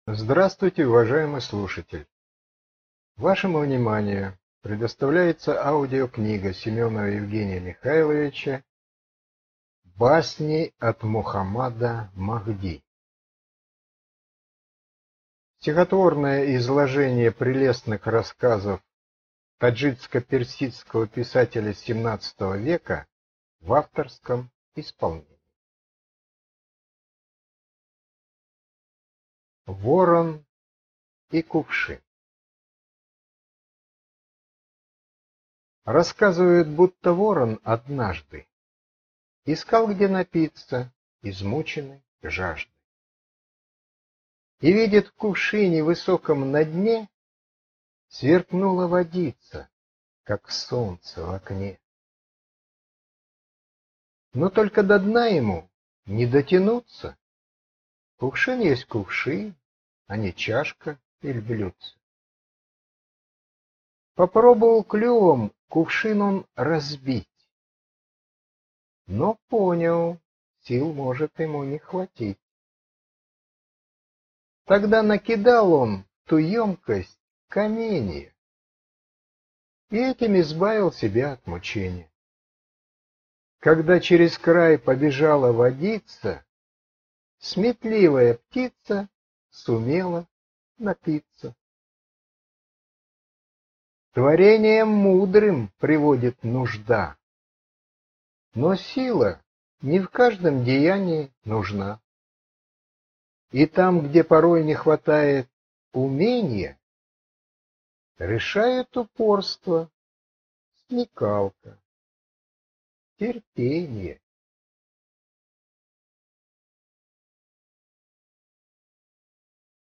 Аудиокнига Басни от Мухаммада Махди | Библиотека аудиокниг